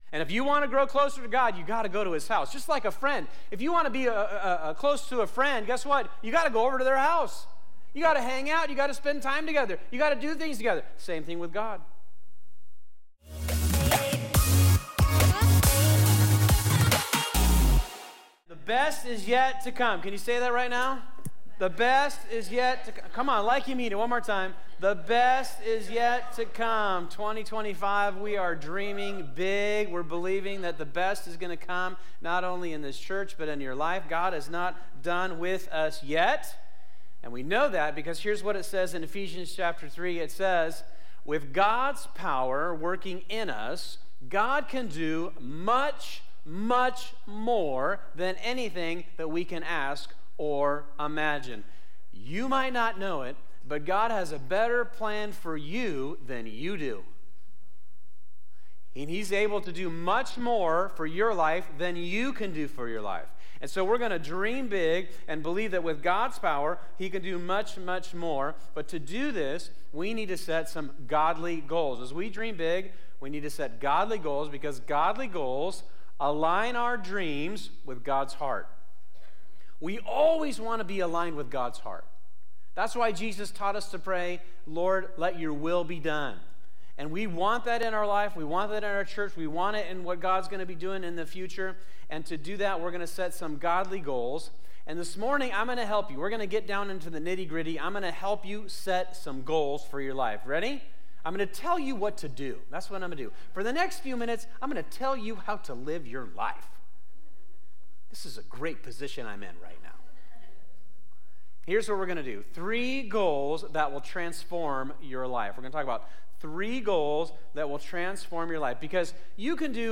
"Dream Big" is our series to kick off 2025 at Fusion Christian Church where we look to set godly goals.